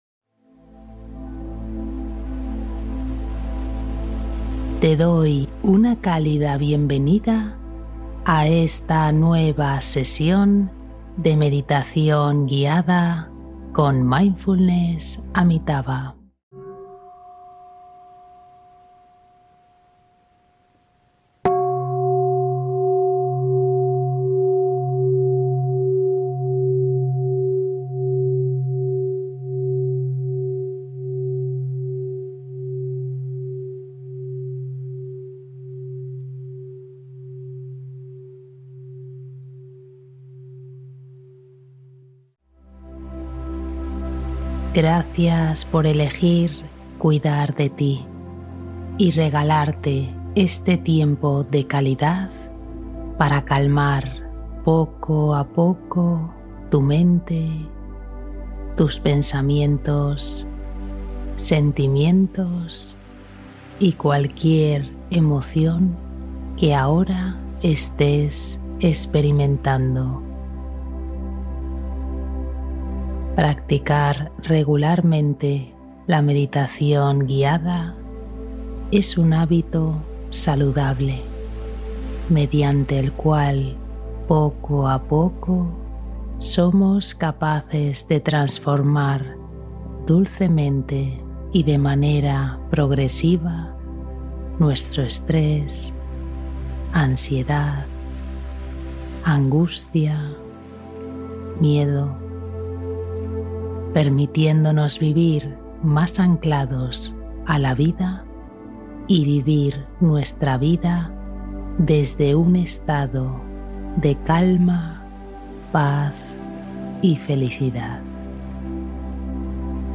Explora tus miedos con atención plena: una meditación de acompañamiento